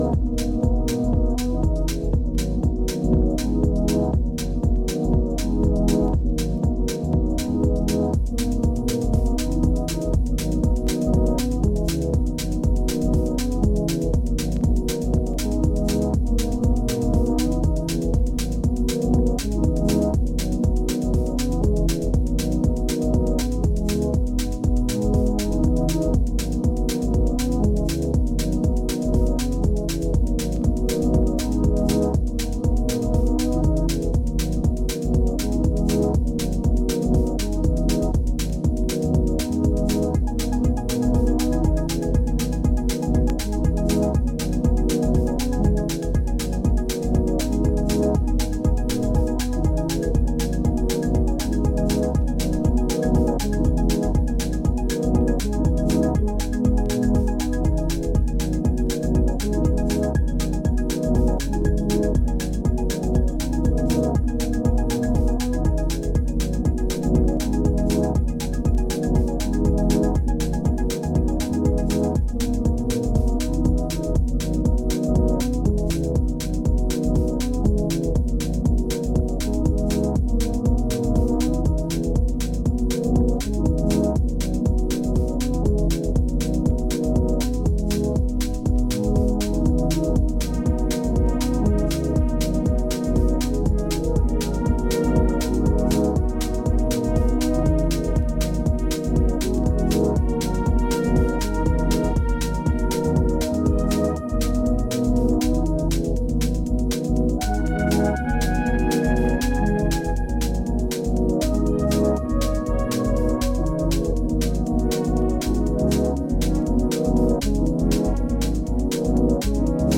頭をもたげるようなレイトナイト感覚が充満した、彼らしい個性が光る1枚です！